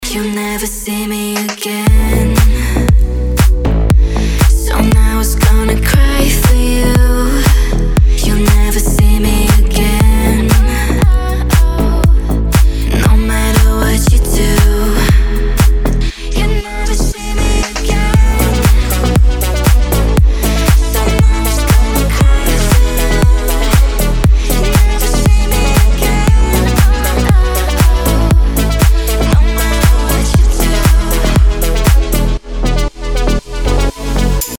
Архив Рингтонов, Танцевальные рингтоны